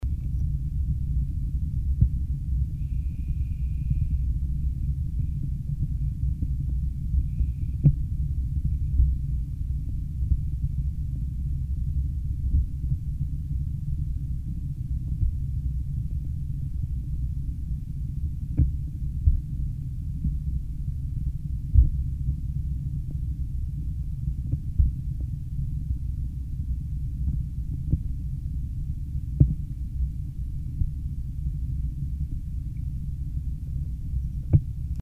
The low frequency noise is the noise of the muscles acting to hold the cable steady, and the slight residual vibration of the piezo disk held by the cable at one end.
Both these tracks were recorded on a HiMD NH700, Mic Lo-Sens 20, where 0dBFS corresponds to -29dBu.
Piezo disk cable held at arms length, disk in air supported by cable.